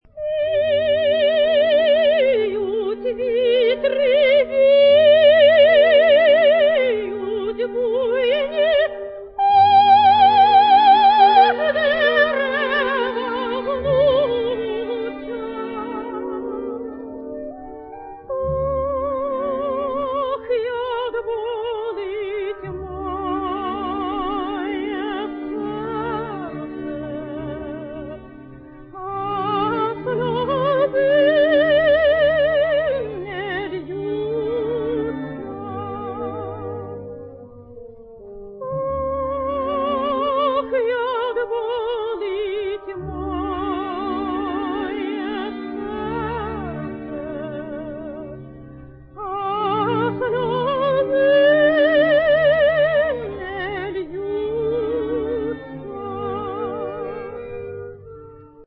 Давайте послушаем в её исполнении начало известной украинской песни «Вiють вiтри, вiють буйнi»: